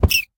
sounds / mob / rabbit / hurt4.ogg
hurt4.ogg